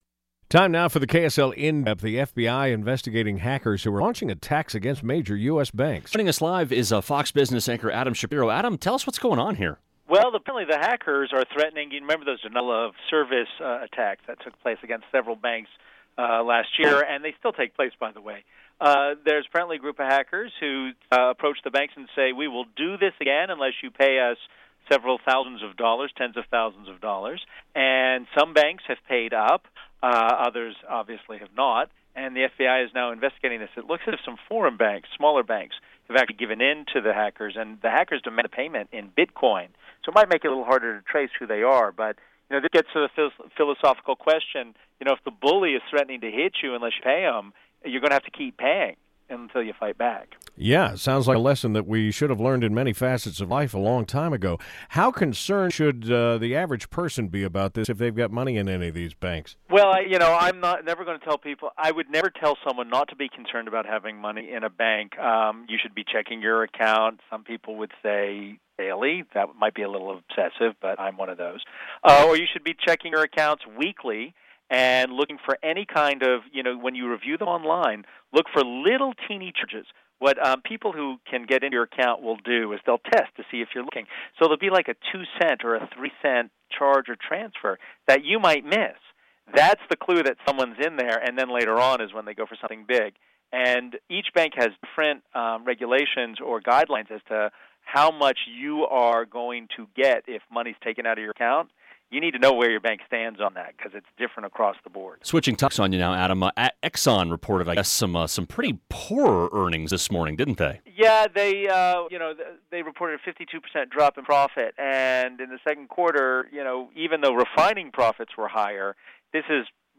The FBI is investigating hackers who are launching attacks against major US banks. Utah's Morning News spoke to Fox Business anchor Adam Shapiro about the hacks, as well as the big drop in reported earnings from Exxon mobile.